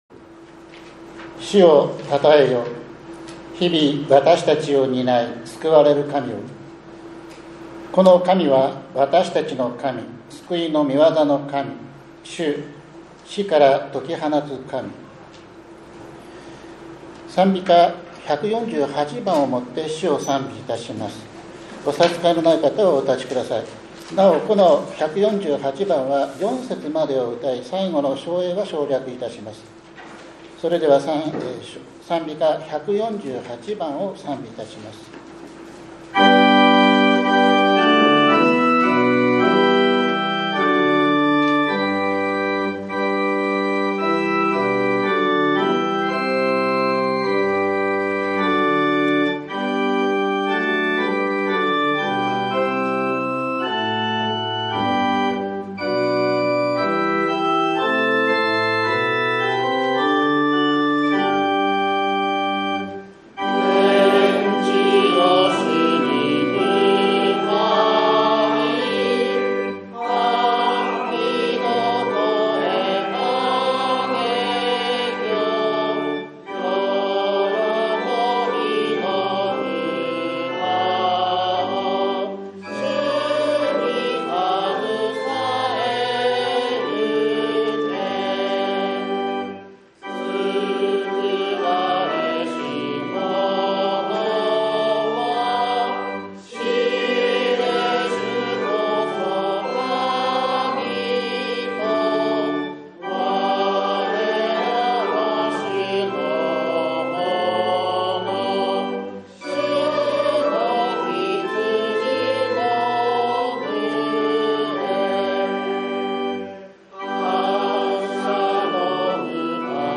５月４日（日）主日礼拝